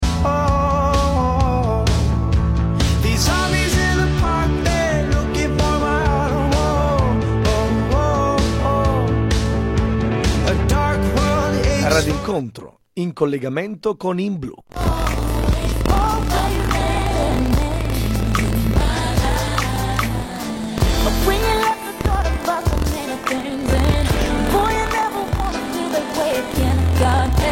Impostato il buffer a 1024, anche oggi distorsione all'aggancio della EXT1, diversa da quella postata ieri.